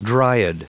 Prononciation du mot dryad en anglais (fichier audio)
Prononciation du mot : dryad